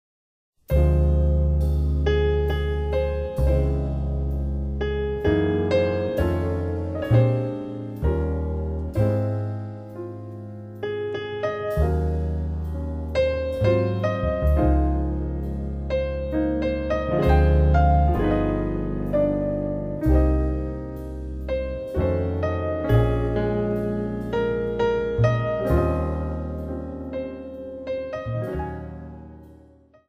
四、採用爵士樂中最受人喜愛的 鋼琴、鼓、貝斯 三重奏編制，演奏受人喜愛的旋律